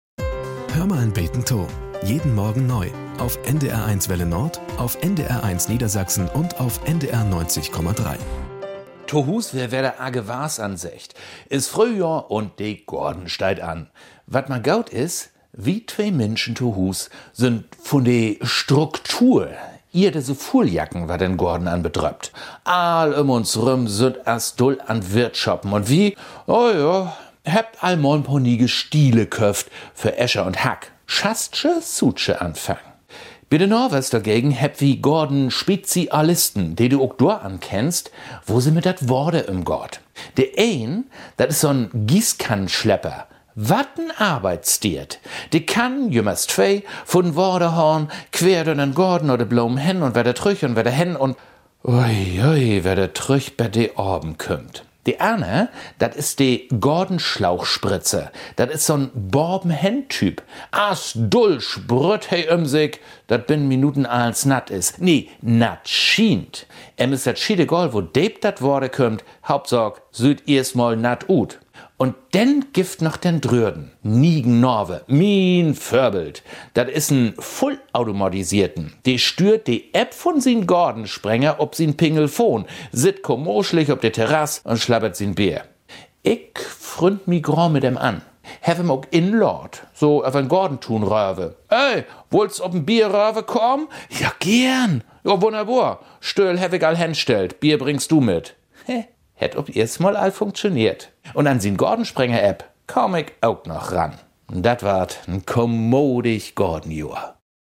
Nachrichten - 01.07.2023